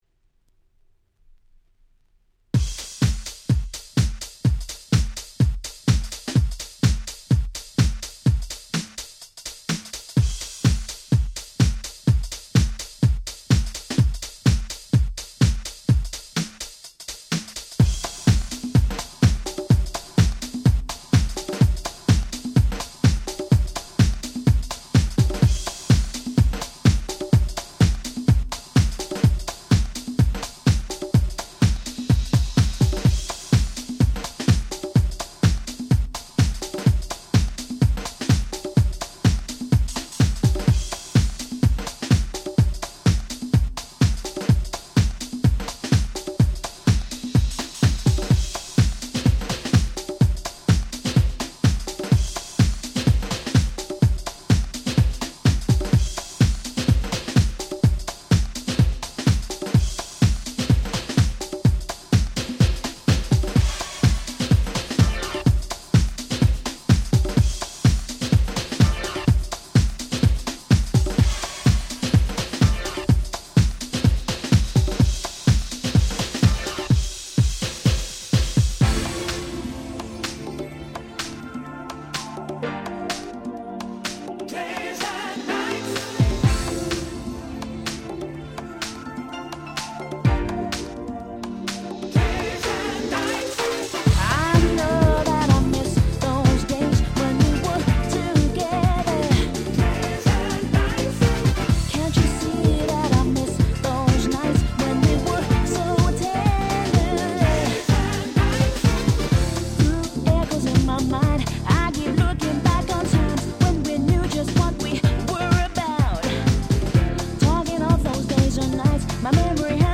00' Super Nice Vocal House !!